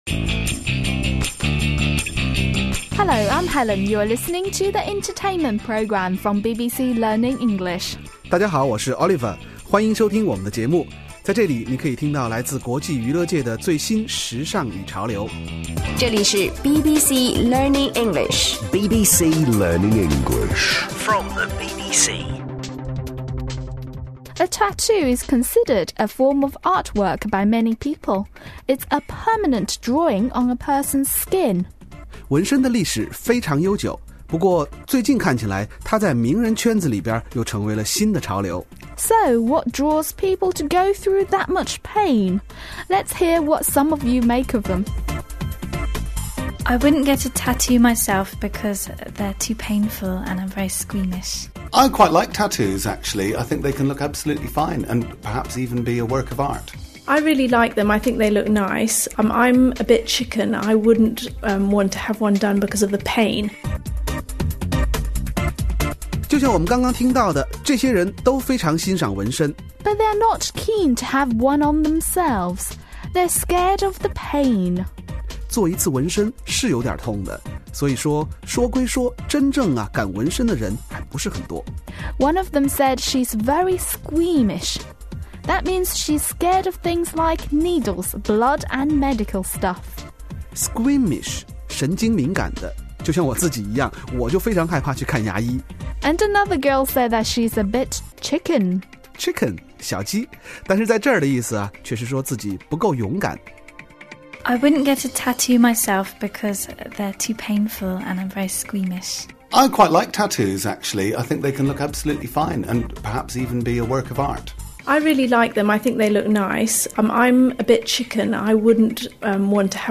有关英国文化、生活、娱乐的5分钟音频精选节目，助你提高听力，增长见识。
Once considered undesirable, tattoos have become a fashion trend in the UK made popular by celebrities. We find out ordinary British people's opinions of this latest trend.